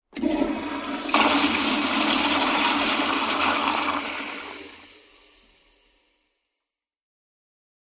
Toilet is flushed. Flush, Toilet Commode, Flush Urinal, Flush